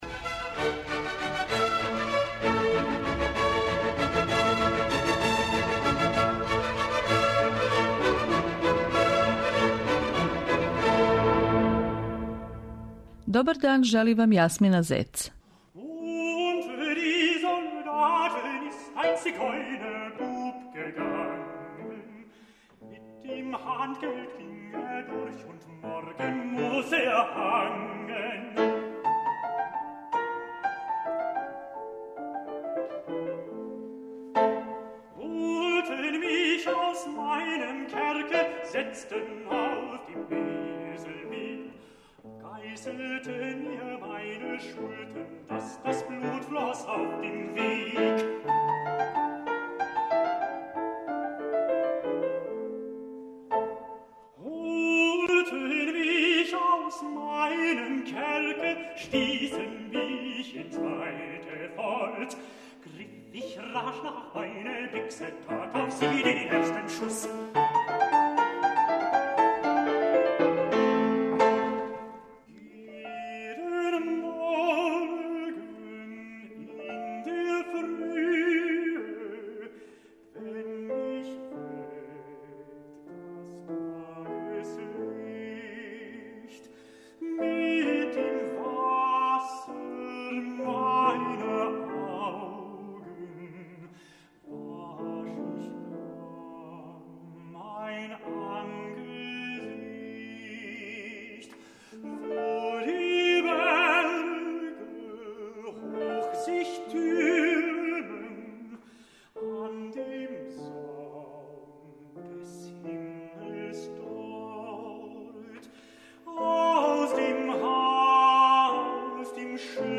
тенор
соло песме немачких романтичара